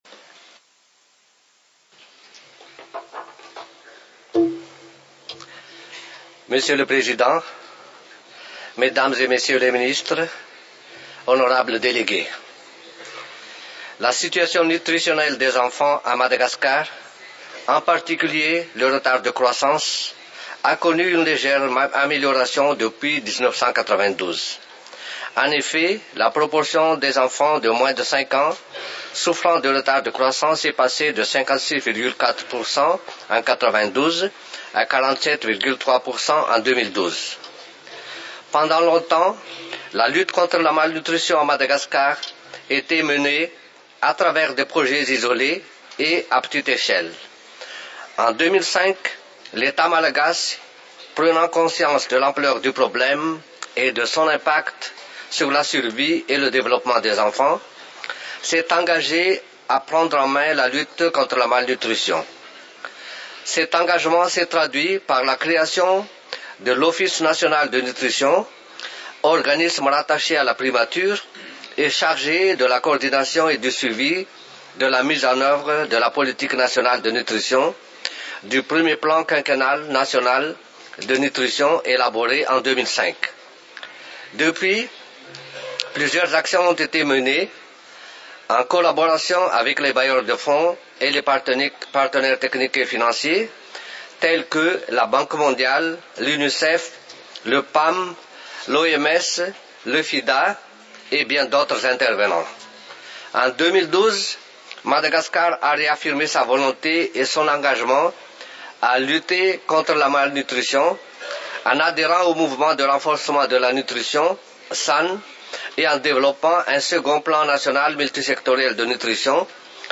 Discursos y declaraciones